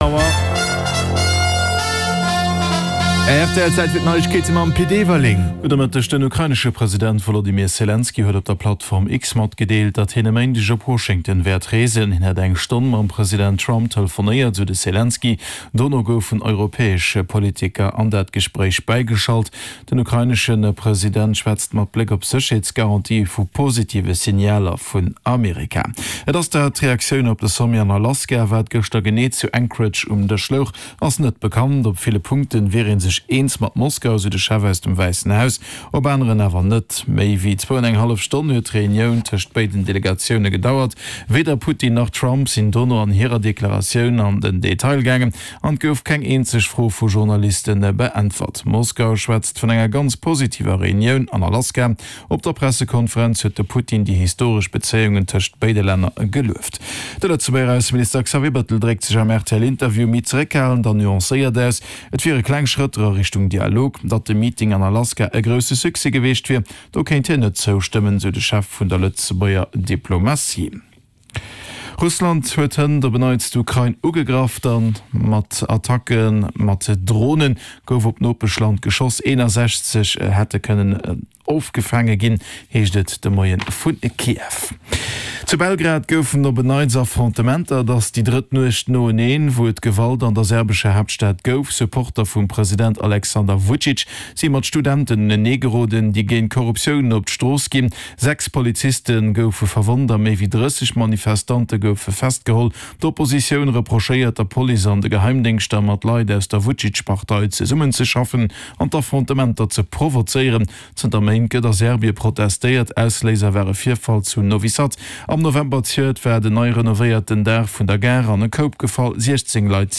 Play Rate Listened List Bookmark Get this podcast via API From The Podcast Den News Bulletin mat allen Headlines aus Politik, Gesellschaft, Economie, Kultur a Sport, national an international Join Podchaser to...